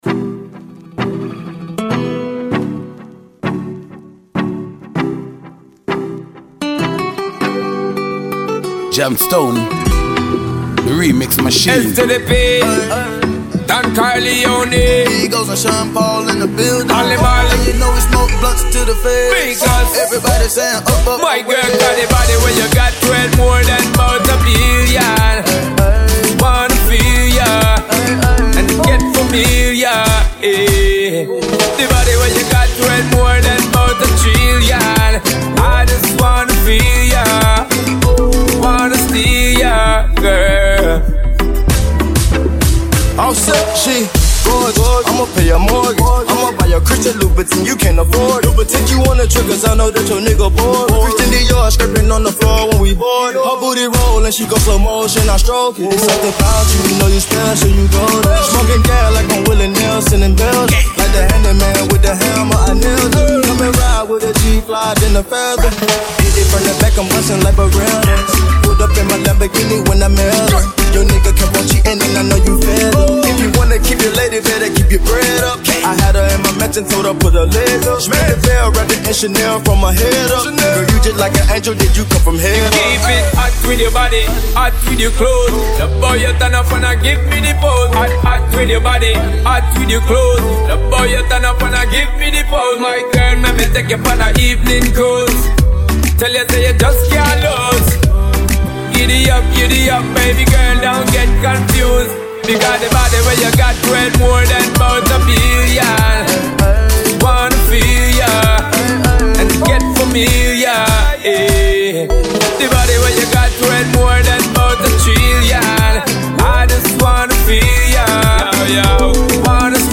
Reggaetón Lento beat